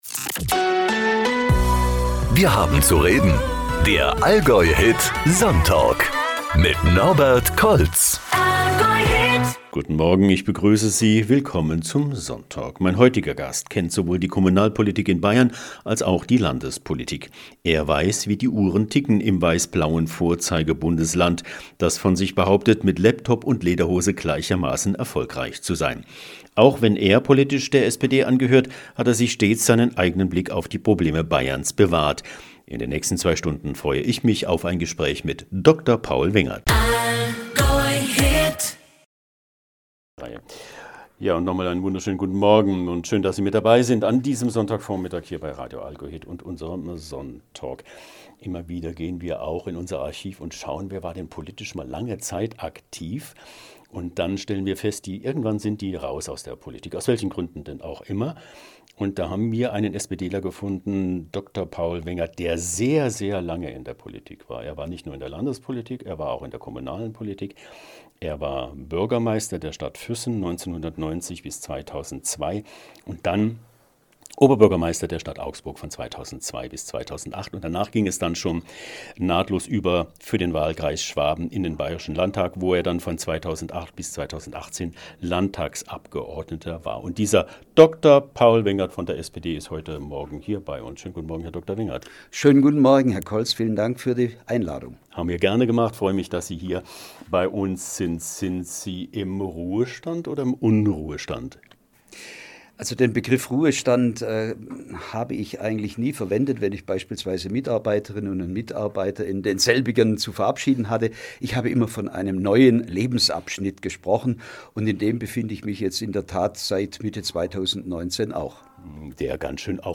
Dr. Paul Wengert zu Gast im Sonntalk bei AllgäuHIT